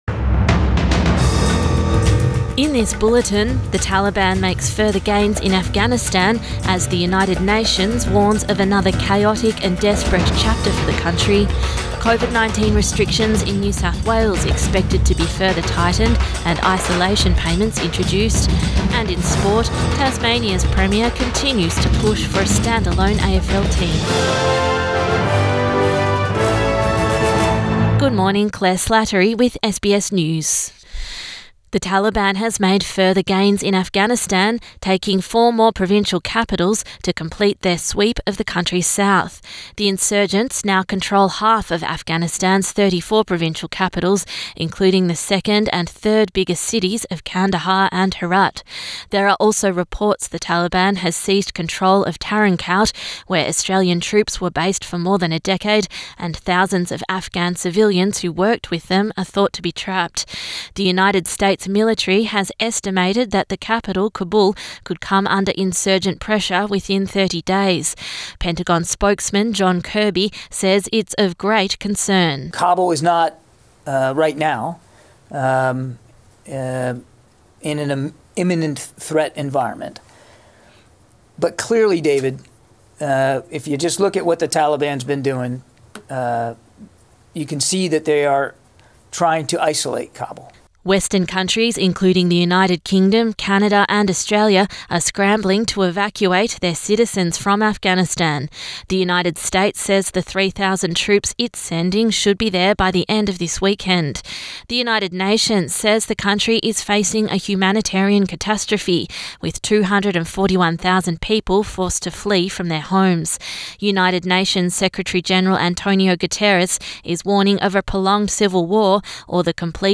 AM bulletin 14 August 2021